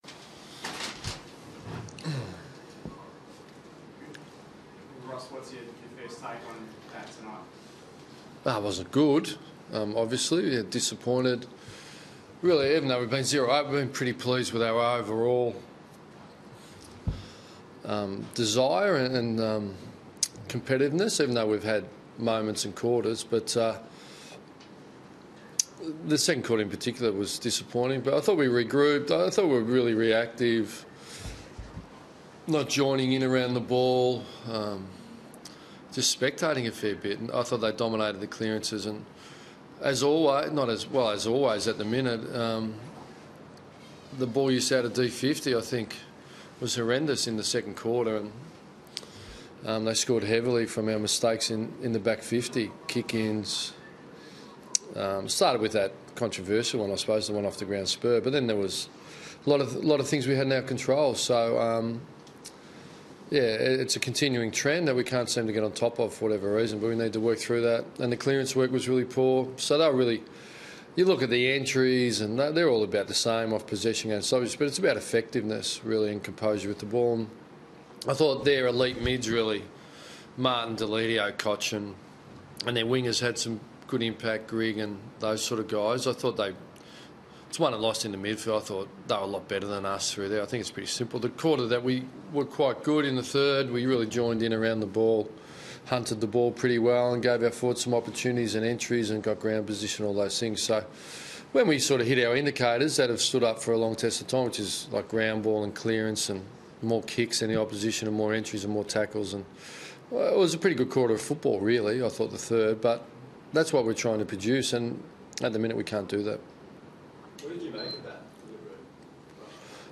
Ross Lyon spoke to the media following the loss against Richmond